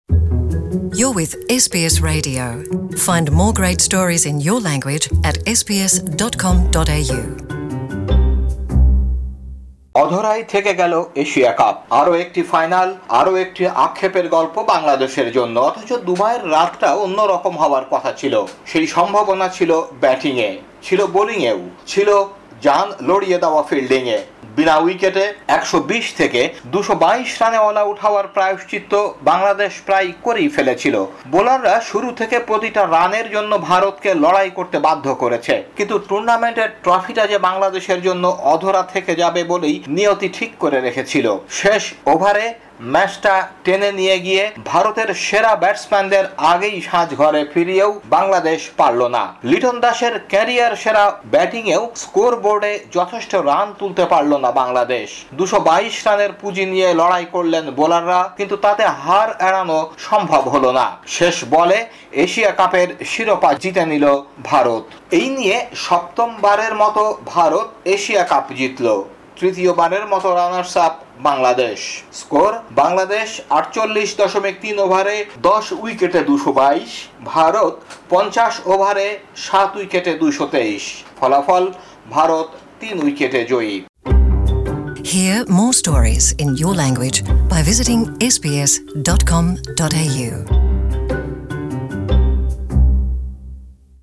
ম্যাচ রিপোর্টটি